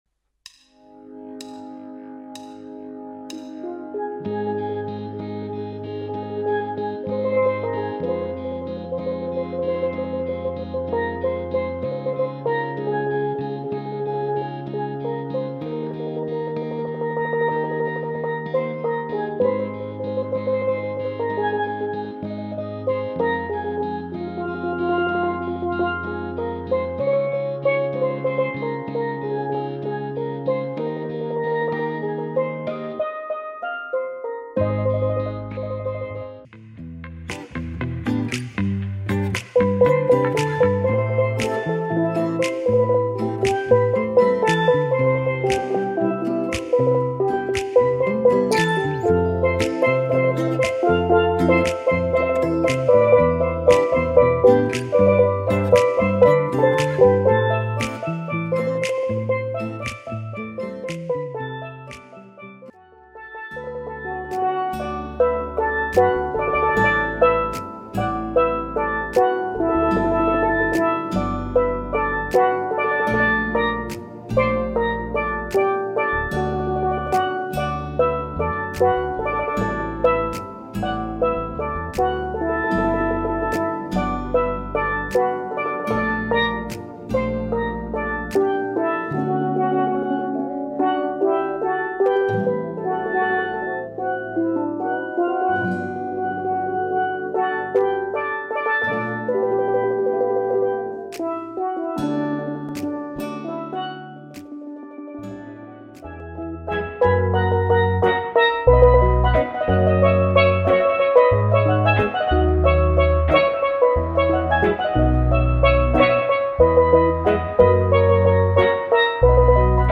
soca, calypso & popular songs on the steel pan
• Solo
Steel Pannist with professional backing tracks